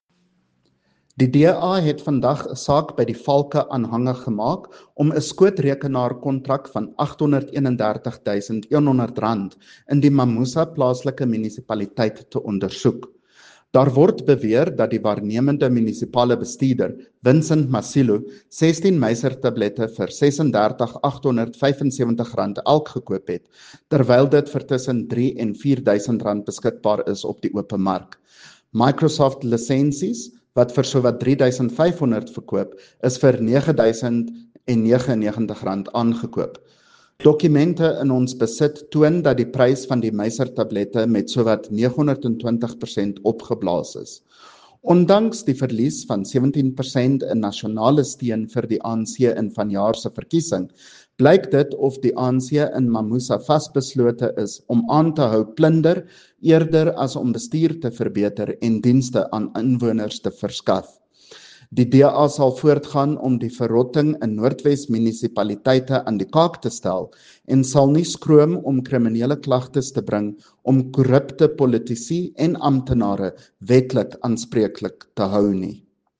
Note to Broadcasters: Please find linked soundbites in